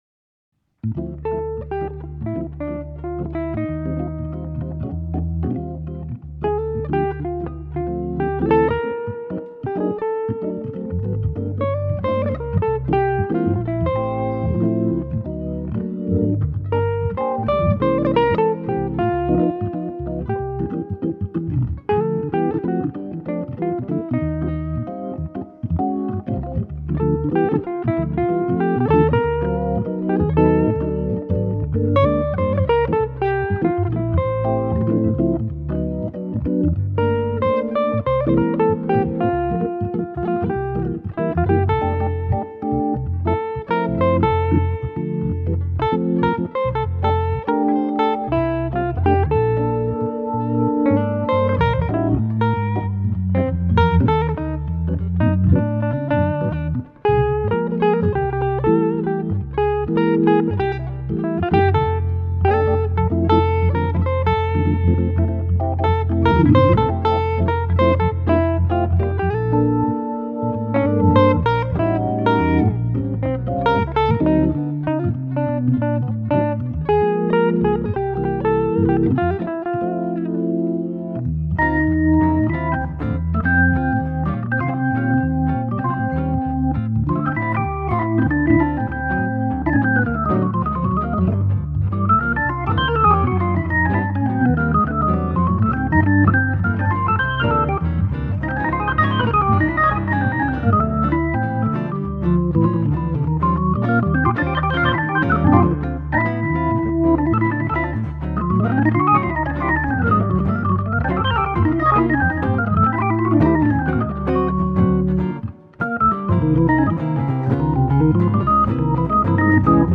proche manouche
l'orgue
la guitare
Pour le DVD c'est plus decontract et en fait.....c'est plus chaleureux....rien à prouver...juste essayer de transmettre qq trucs à des amis organistes.....il n'y a que des premieres prises....voilà comme on devrait toujours faire mais.....on est tellement attendus par certains journalistes tueurs....qu'on fait gaffe et la musique s'en ressent...La on est peinard, à la maison....sans risques....sans pression....une tite clope....unchti cassis eau fraiche....une bonne rigolade....on bosse pour des gens qui ont envie d'apprendre.....pas de chercher la petite bete.....c'est chouette ainsi.